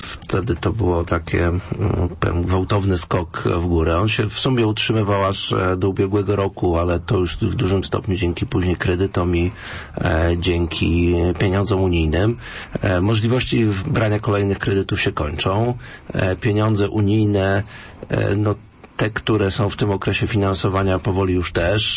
- Problemy zaczęły się w latach 2008-2009 i jeśli dzisiaj nic z tym nie zrobimy, za chwilę będzie jeszcze gorzej - mówił w poniedziałek na naszej antenie w programie "Z jakiej racji" prezydent Grobelny.